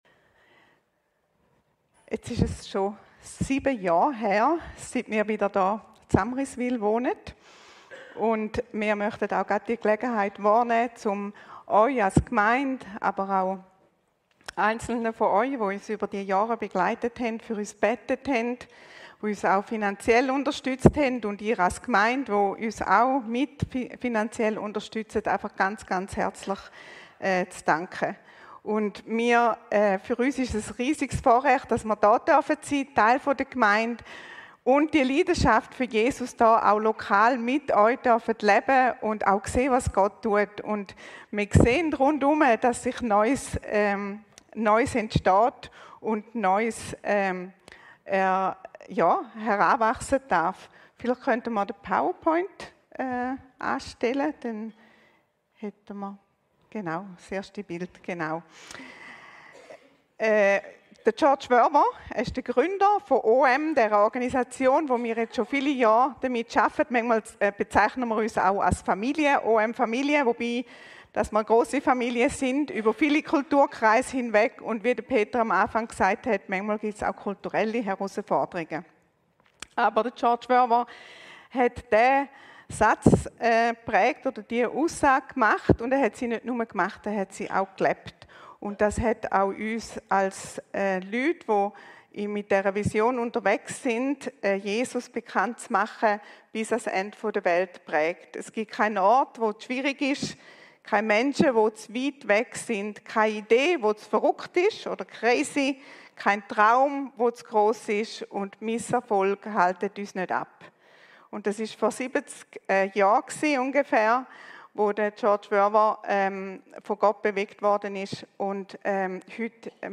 Predigten
Die neueste Predigt